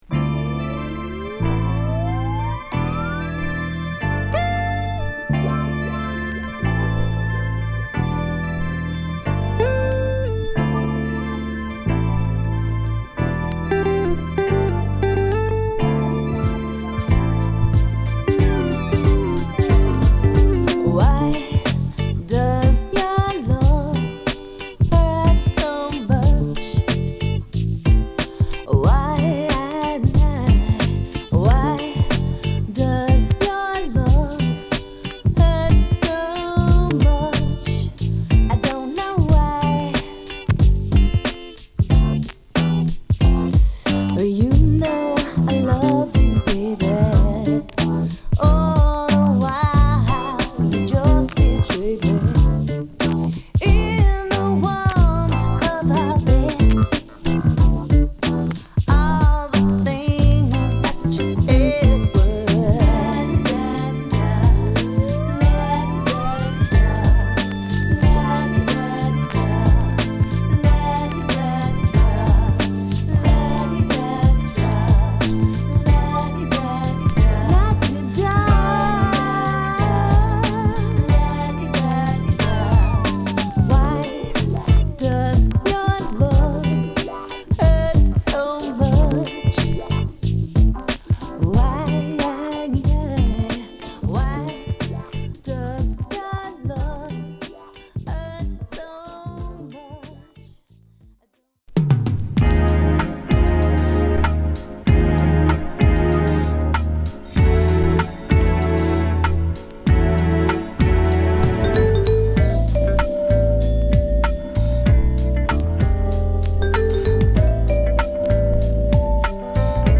クールでメロウなグッドトラックを収録！